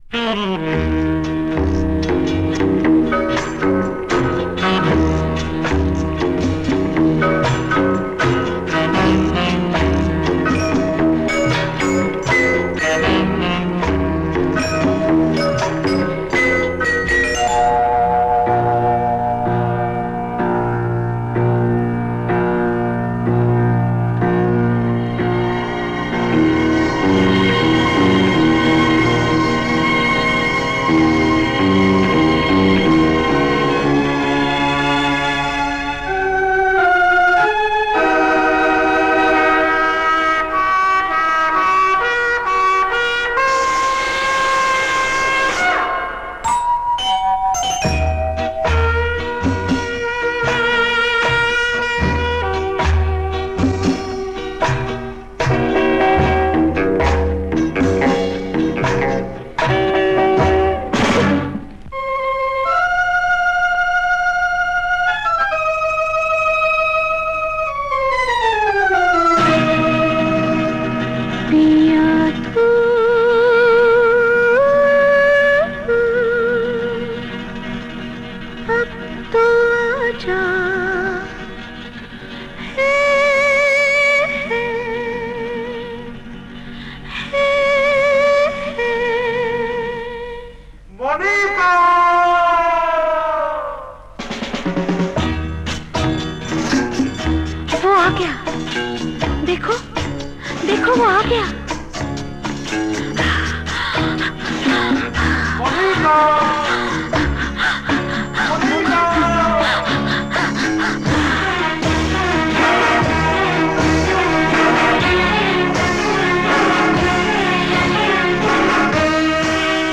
un classique du Bollywood, version 2